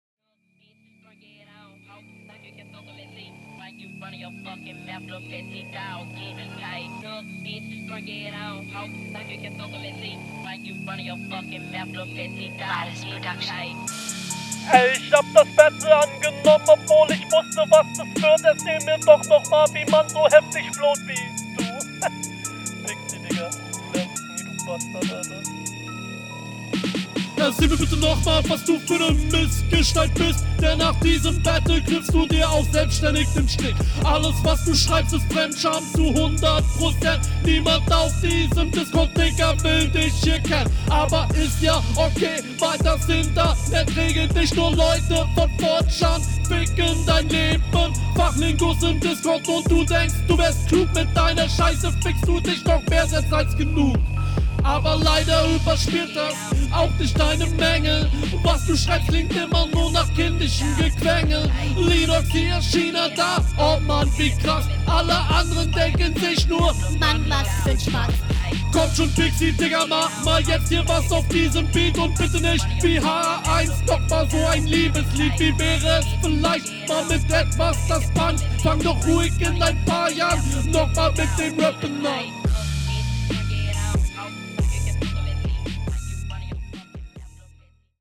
Sicker Beat! Flow auch unerwartet stabil auf diesem Instrumental!
Flow: Recht sauber durch geflowt, Betonungen gefallen mir.